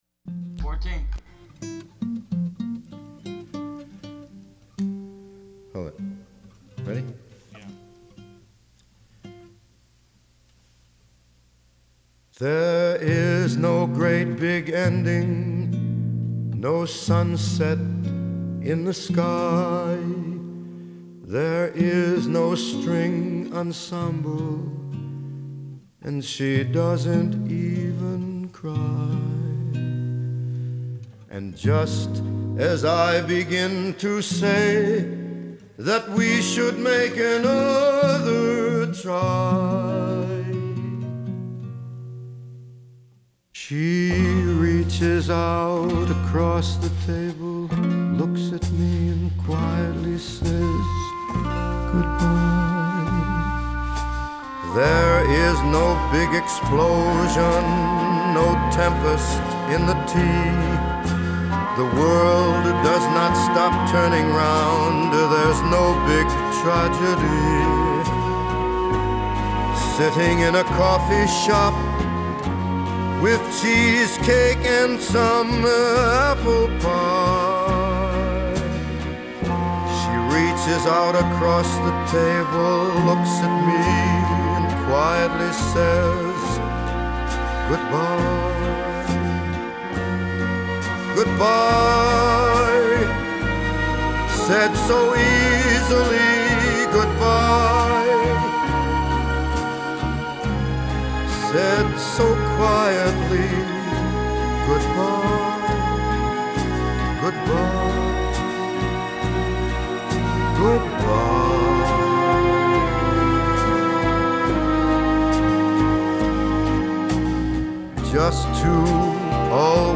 Genre : Lounge, Électronique